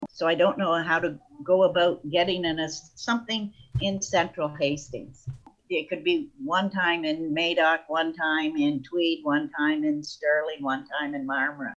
Hastings County virtual meeting, May 28, 2020 (Hastings County Facebook Zoom)
At the virtual meeting of Hastings County Council on Thursday, Mayor of Tweed Jo-Anne Albert raised the issue saying she felt the central area had “been left out “when it came to the testing for the coronavirus.